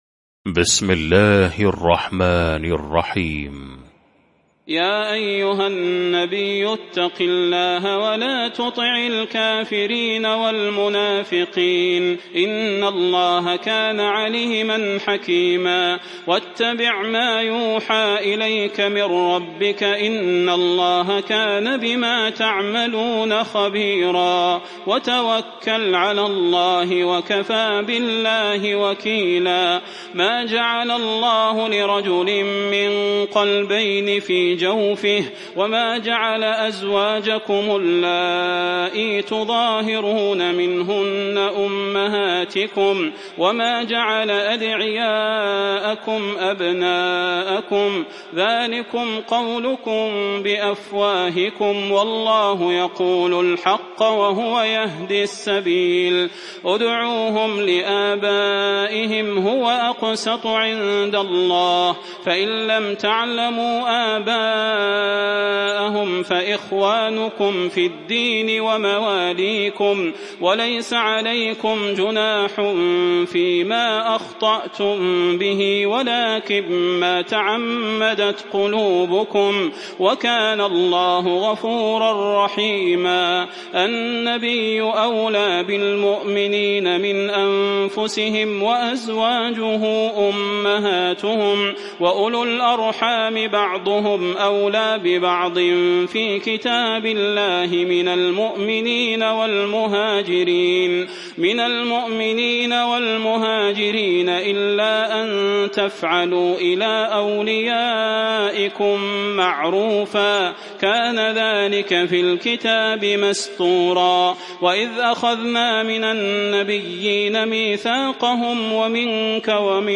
المكان: المسجد النبوي الشيخ: فضيلة الشيخ د. صلاح بن محمد البدير فضيلة الشيخ د. صلاح بن محمد البدير الأحزاب